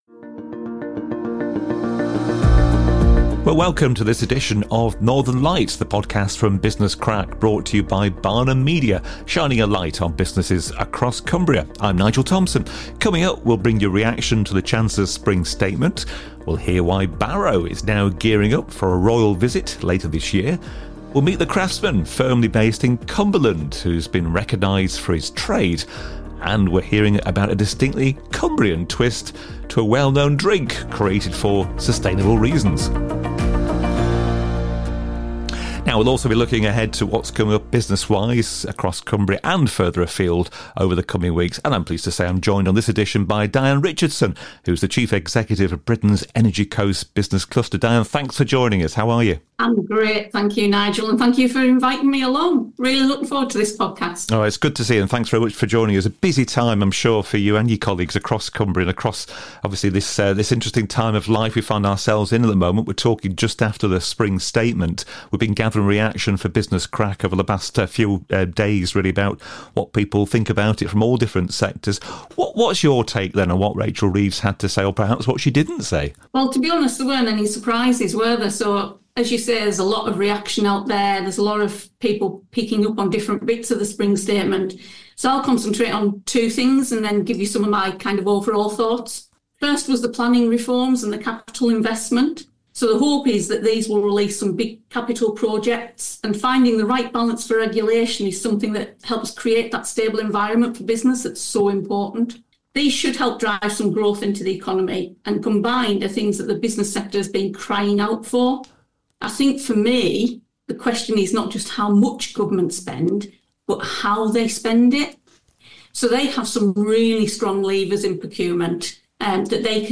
Quality news and interviews from Cumbria’s business community.
We’re out across the county to bring you a true taste of what’s happening and gather opinions from those in the know.